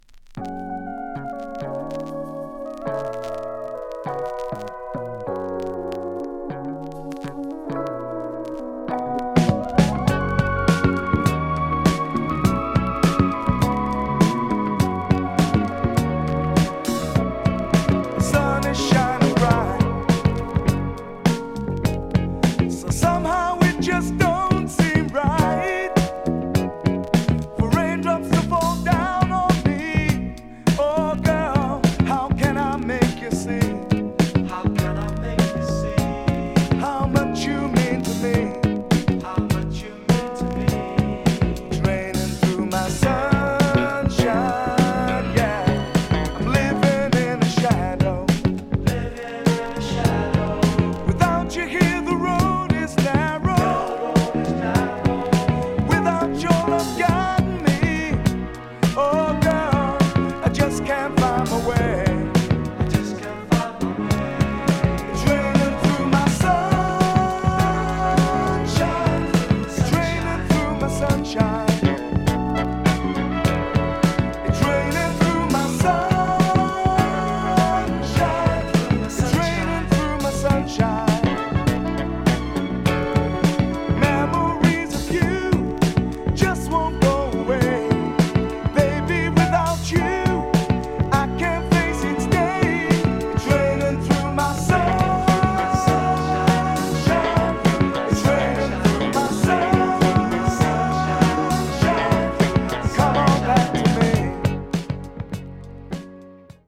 英国アーバン・スムージーソウルの大名曲。